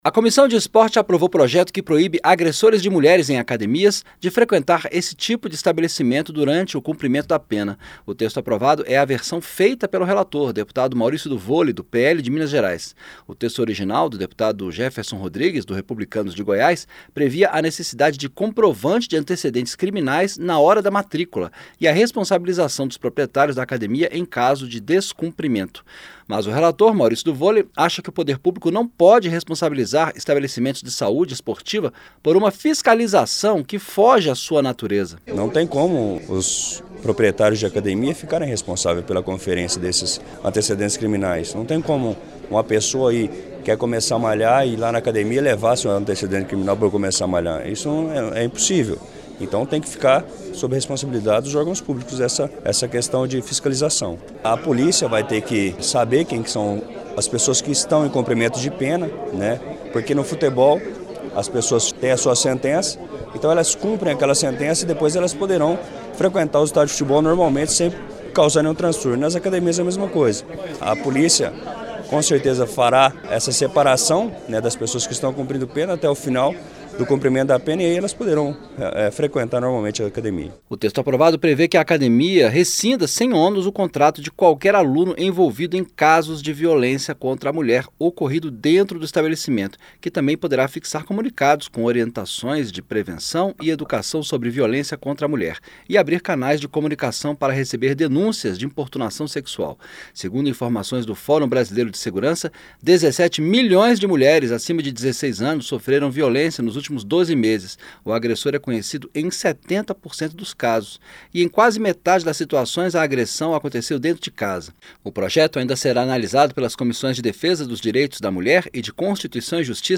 PROPOSTA DETERMINA QUE AGRESSORES QUE ESTIVEREM CUMPRINDO PENA DEIXEM DE FREQUENTAR ACADEMIA ONDE VIOLÊNCIA CONTRA A MULHER FOI COMETIDA. ACOMPANHE A REPORTAGEM